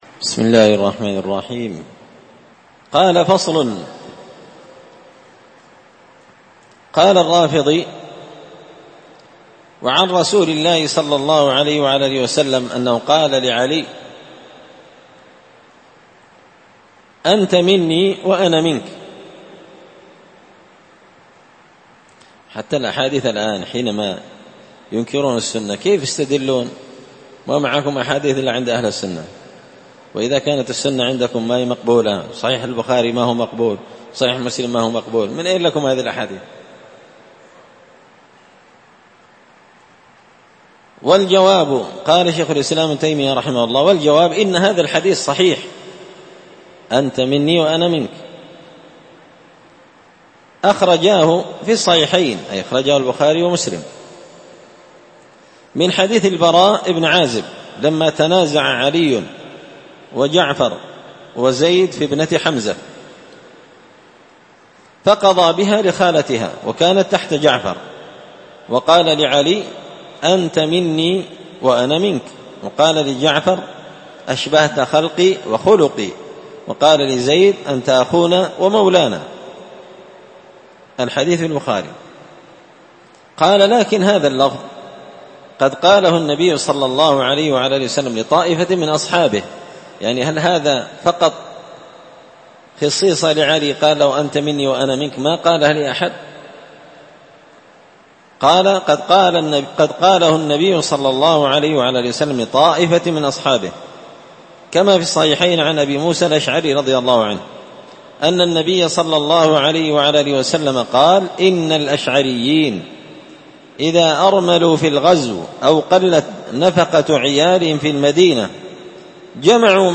الأربعاء 11 ذو القعدة 1444 هــــ | الدروس، دروس الردود، مختصر منهاج السنة النبوية لشيخ الإسلام ابن تيمية | شارك بتعليقك | 10 المشاهدات
مسجد الفرقان قشن_المهرة_اليمن